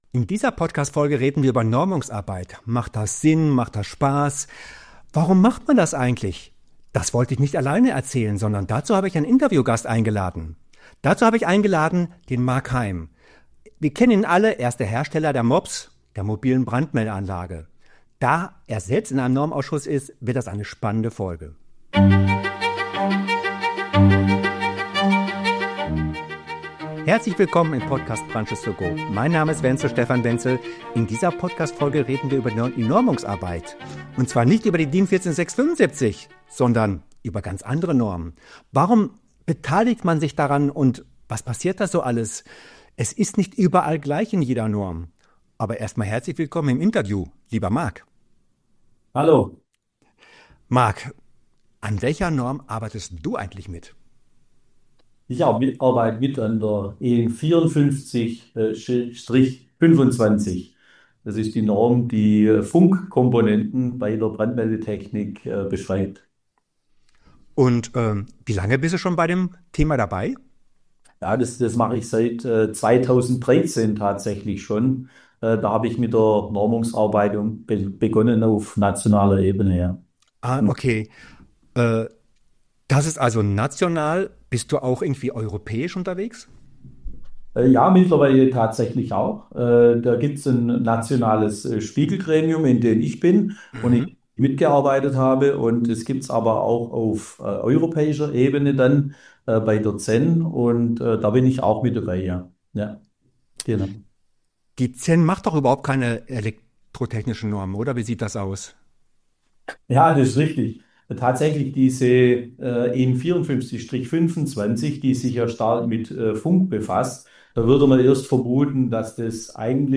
Im Gespräch geht es darum, warum Normungsarbeit wichtig ist, wie Normen entstehen und warum Mitgestalten oft mehr bringt, als nur Anwenden.